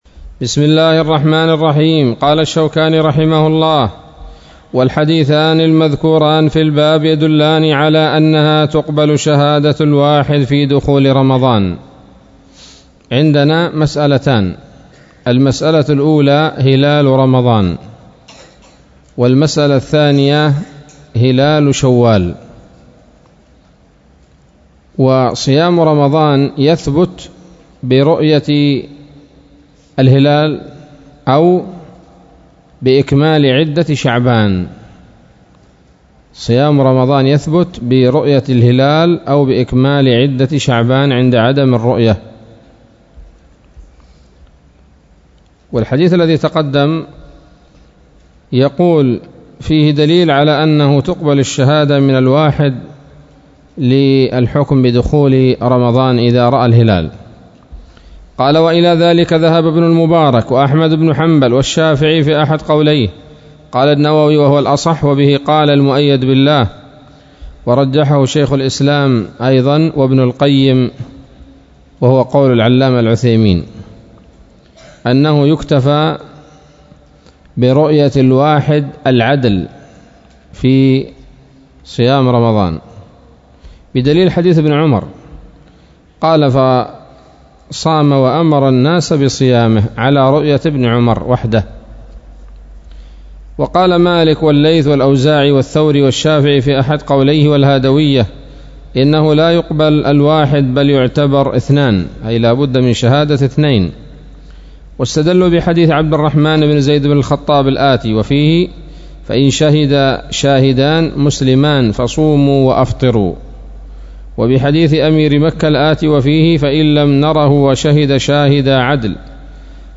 الدرس الثاني من كتاب الصيام من نيل الأوطار